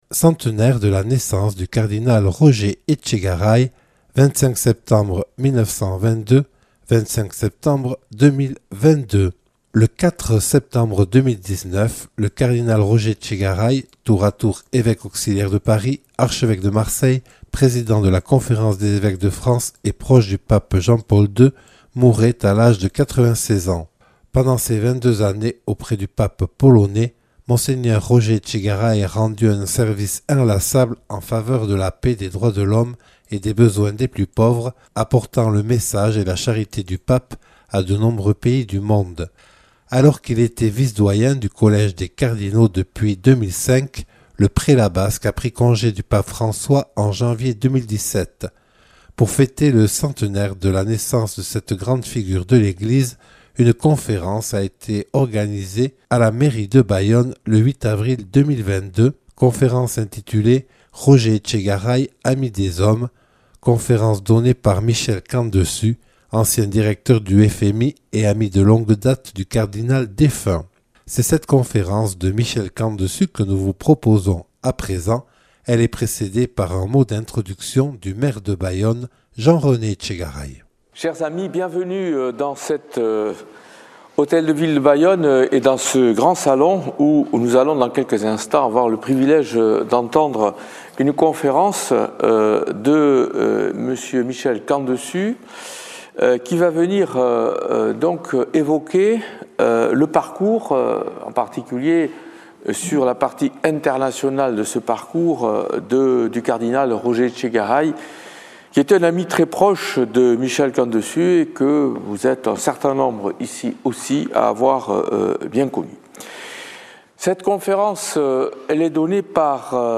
A l’occasion du centenaire de la naissance du Cardinal Roger Etchegaray (25 septembre 1922 à Espelette), Michel Camdessus, ancien président du Fonds Monétaire Internationale, a donné une conférence à la Mairie de Bayonne le 8 avril 2022 à la Mairie de Bayonne. Conférence précédée d’un mot d’introduction de Jean-René Etchegaray, Maire de Bayonne.